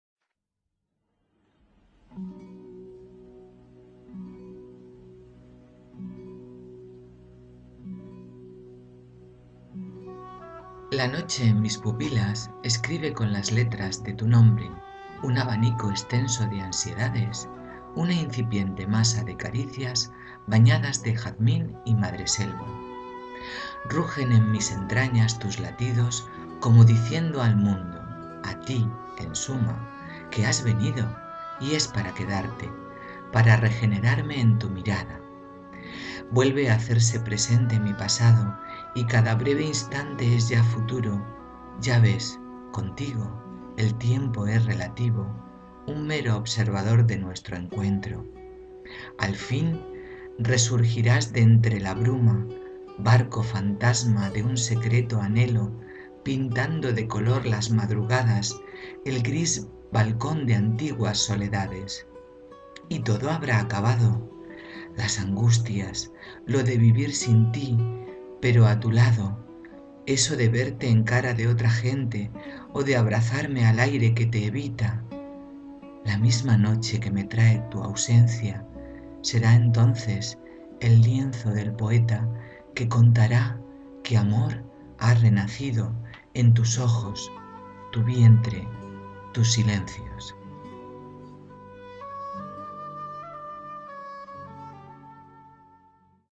Recitado